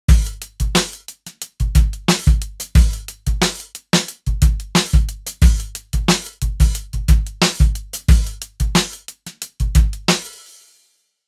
Vintage drums for modern beats
Warm, dry and detailed
Built with a modern, dry sound in mind Sun Drums is packed with breakbeats, booming hip-hop, fat pop drums and everything in between.